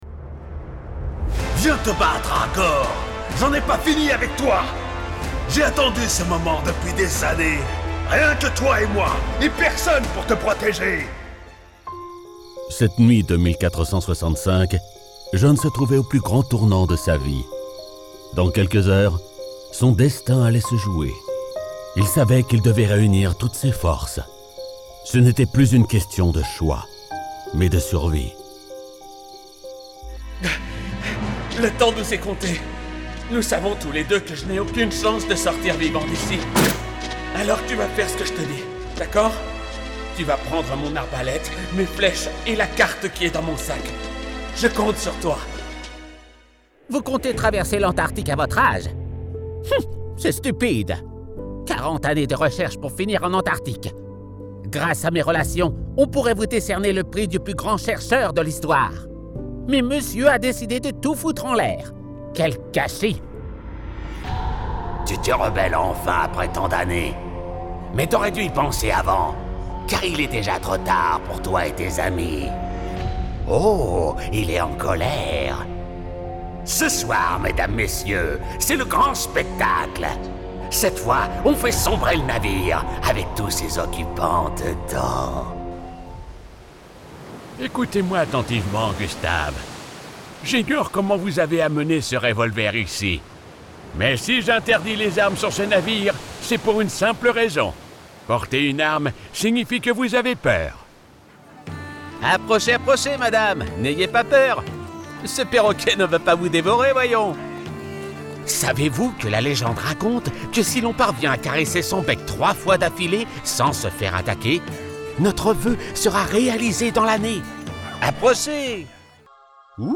Polyvalente, Naturelle, Cool, Profonde, Chaude